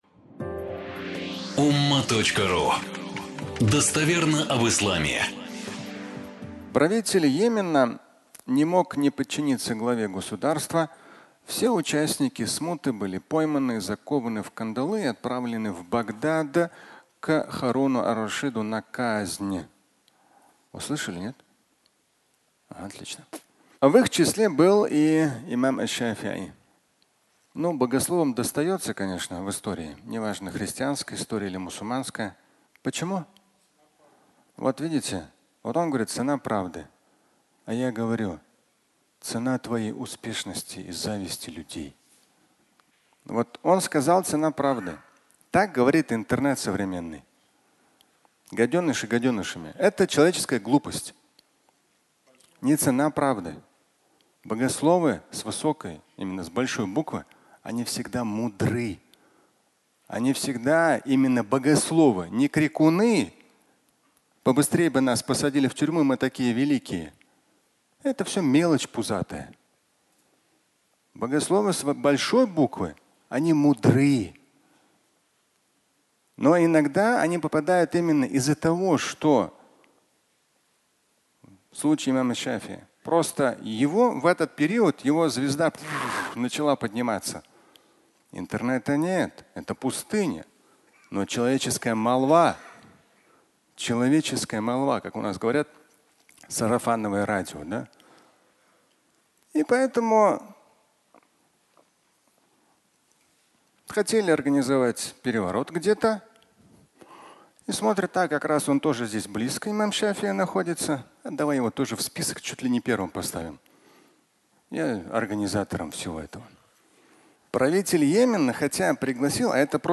Часть 1 (аудиолекция)
Фрагмент пятничной лекции